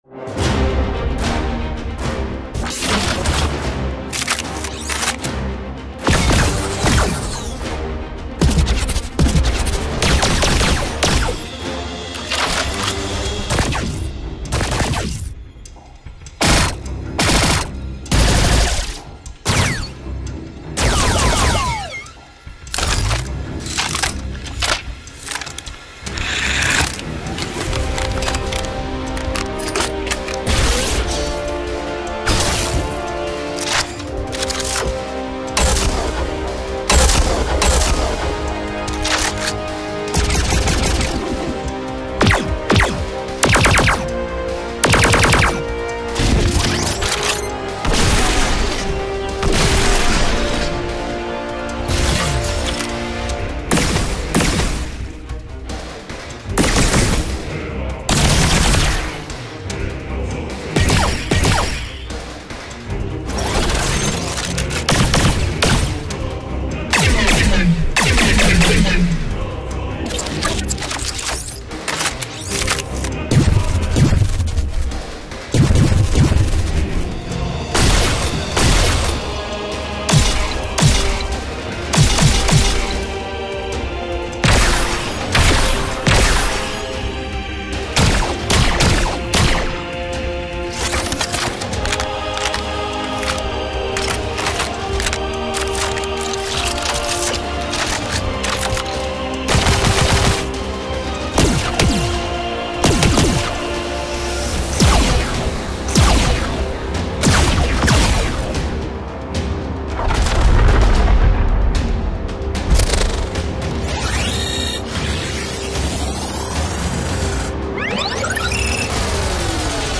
探索适合您未来射击游戏的音效，包括装弹、机甲、射击、强化和失效等各种极具特色的音效
– 高科技武器机制、精心设计的科幻装弹音效以及数百种武器射击音效
– 多种武器射击类型，包括扭曲弹、霰弹枪弹、重型弹、激光弹、爆能枪弹、小型弹等等
– 超过 700MB 的高品质音频内容，全部以 96kHz/24bit 录制和设计
– 科幻路过音效 (21 个文件)
Sci-FiWeaponsPack1AudioTrailer.mp3